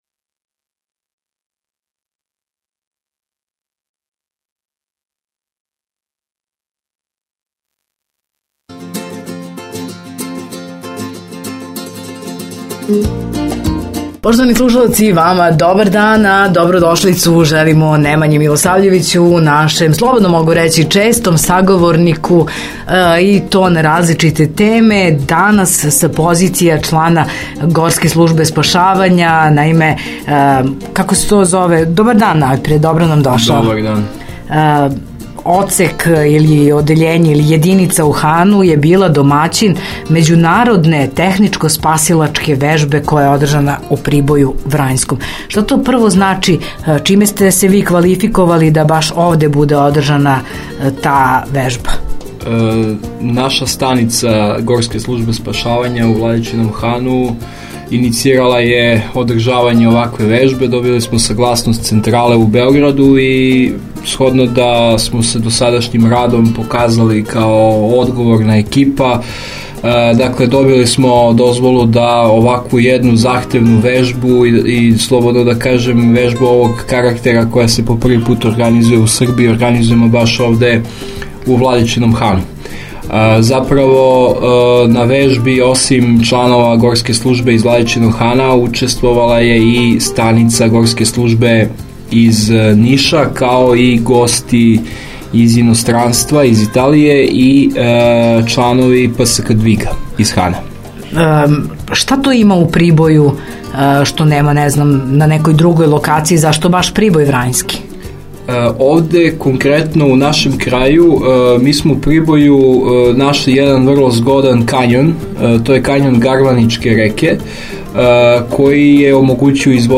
Ceo razgovor